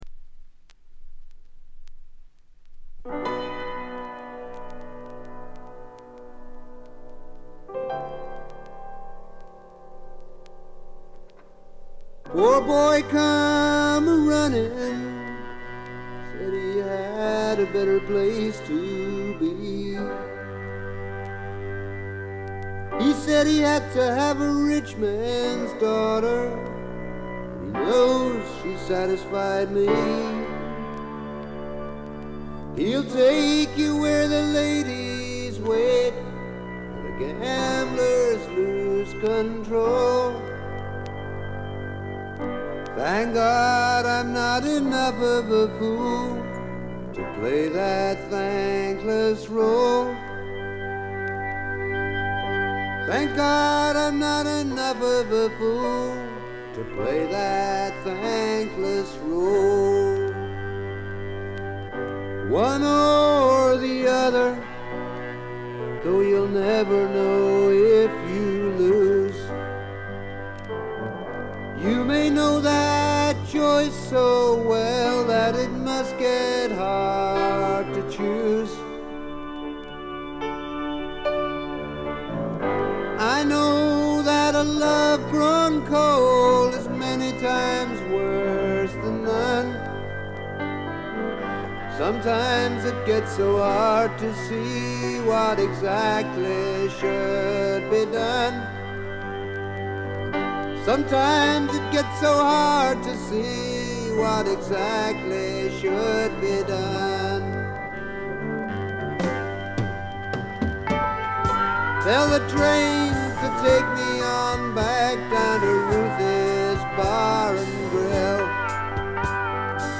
わずかなノイズ感のみ。
録音もベルギーで行われており、バックのミュージシャンも現地組のようです。
試聴曲は現品からの取り込み音源です。
Vocals, Backing Vocals, Guitar, Harmonica